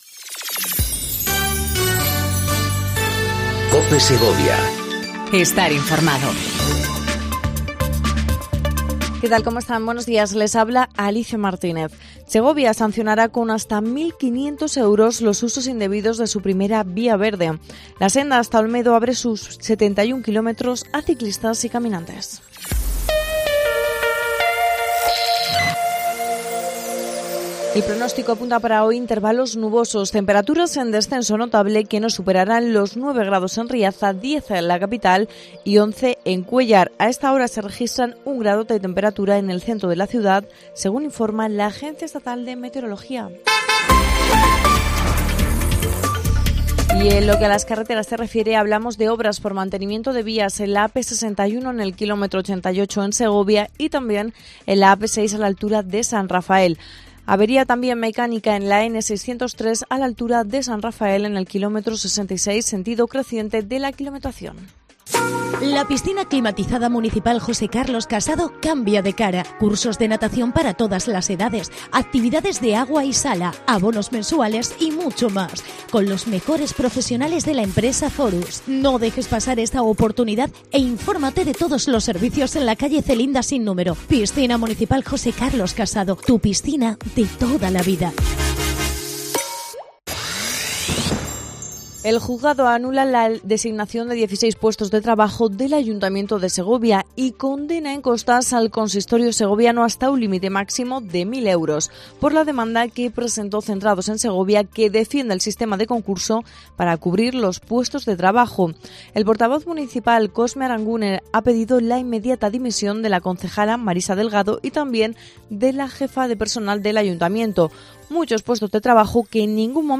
AUDIO: Segundo informativo local en cope segovia